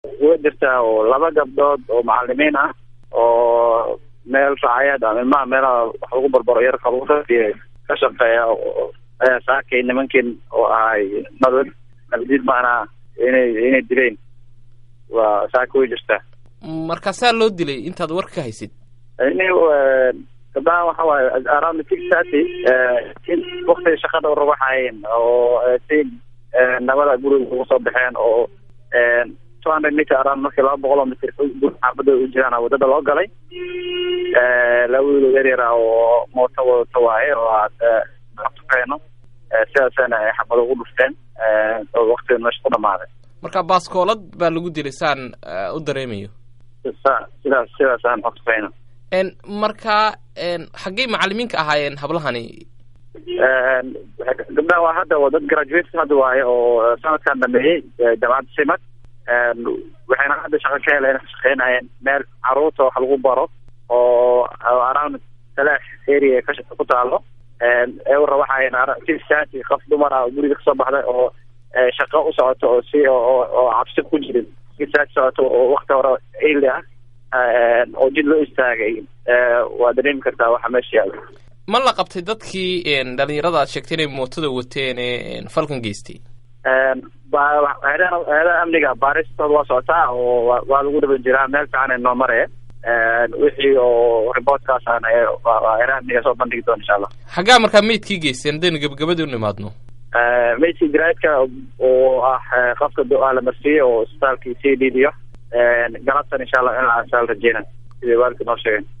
Waraysiga Guddoomiyaha Degmada Dharkeynlay